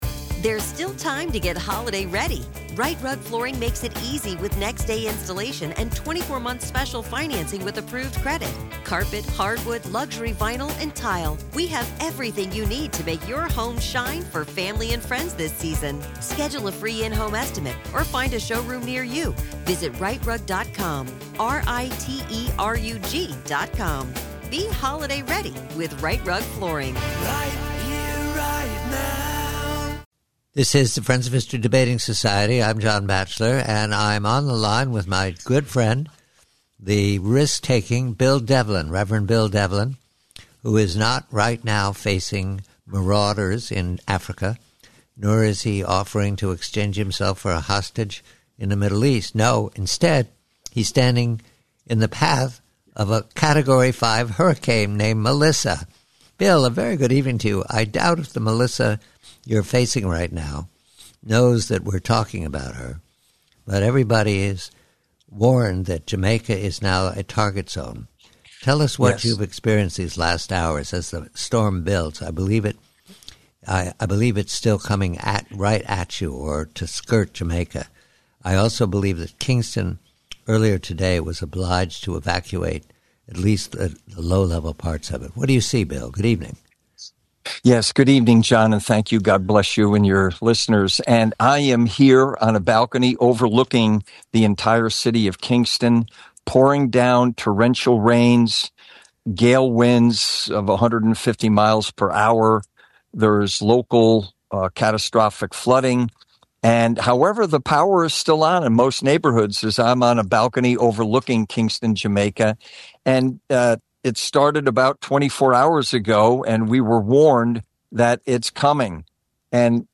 Host John Batchelor interviews